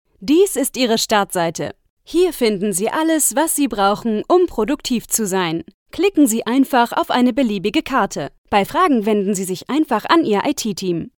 Young, Natural, Playful, Accessible, Friendly
E-learning